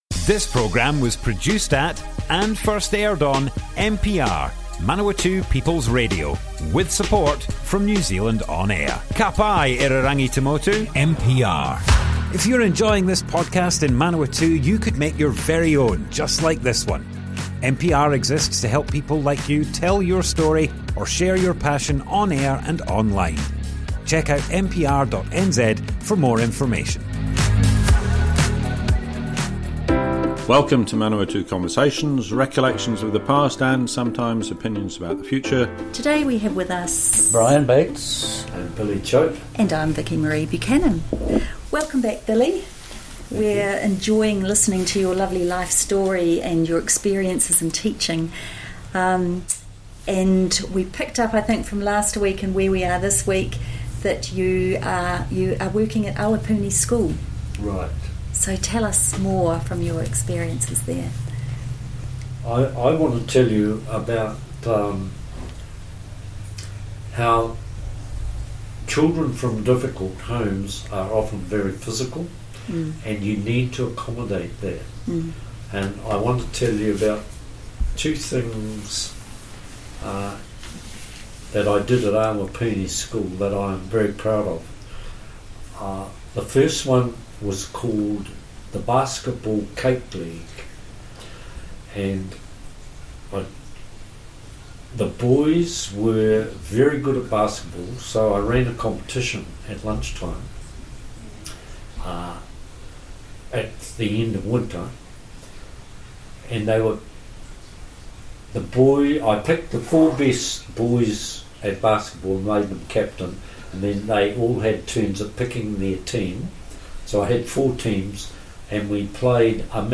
Teacher and visiting teacher - Manawatu Conversations Object type Audio More Info → Description Broadcast on Manawatu People's Radio, 21st November 2023, Part 3 of 5.
oral history